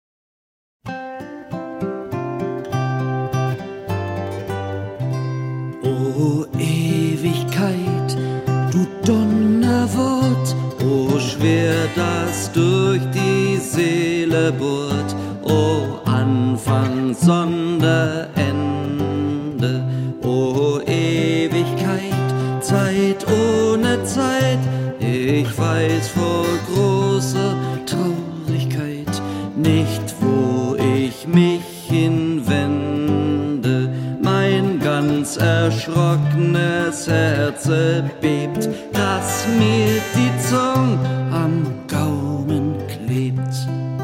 6donner guitare.1.mp3